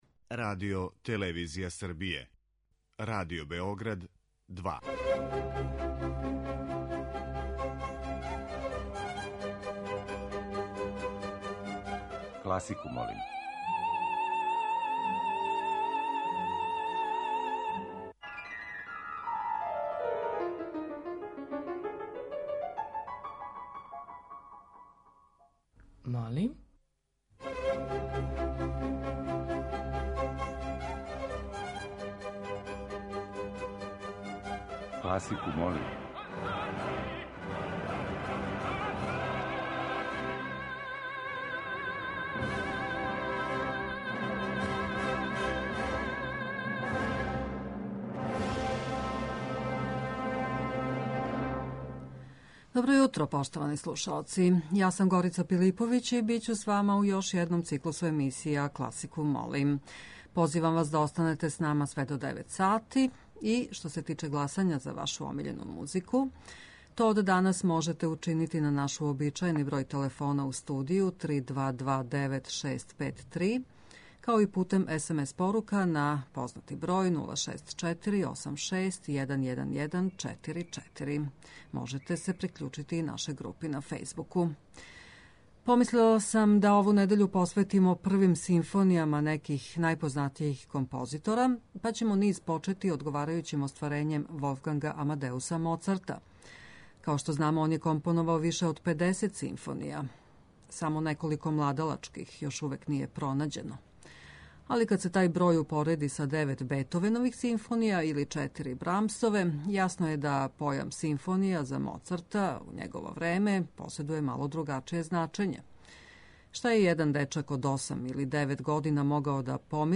Прве симфоније неких најпознатијих композитора